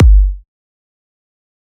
EDM Kick 14.wav